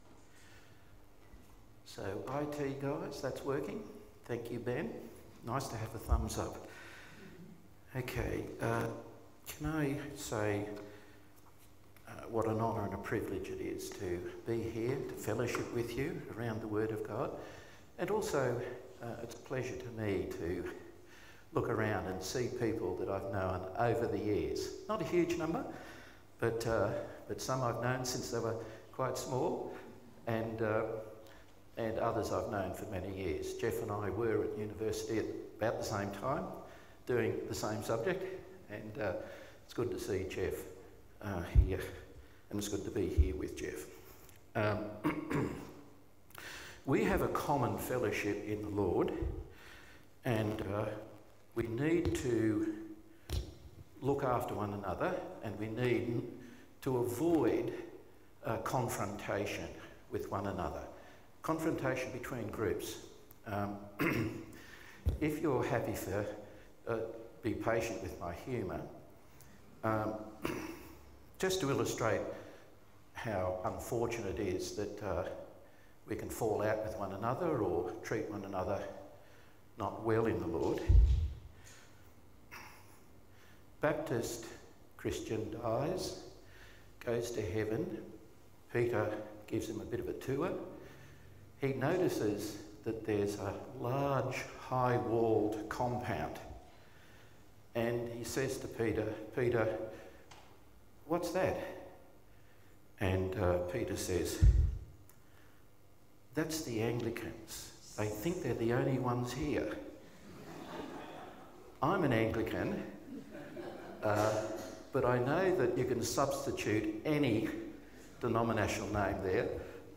The first in a series of five sermons.
Service Type: AM Service